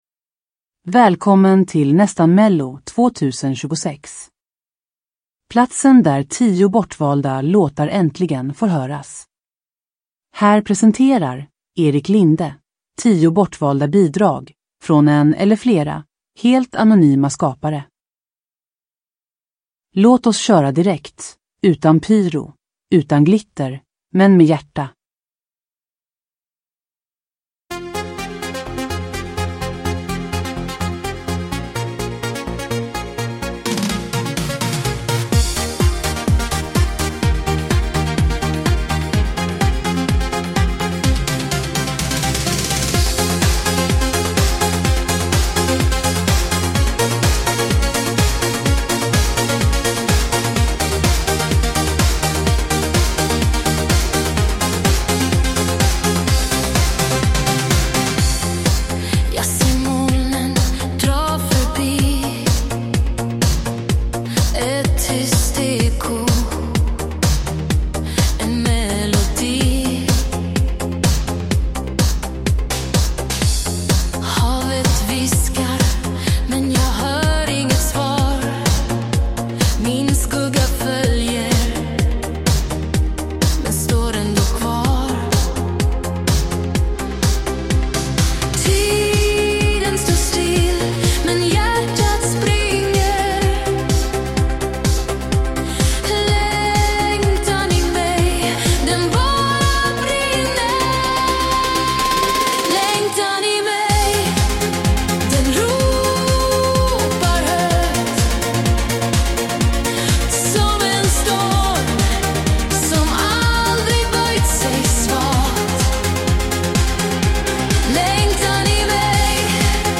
• Ljudbok